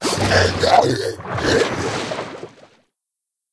c_seasnake_dead.wav